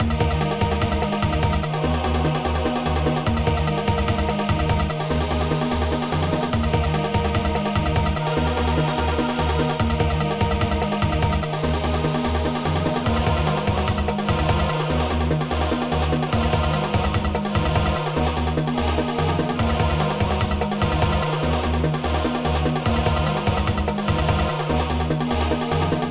mod (ProTracker MOD (6CHN))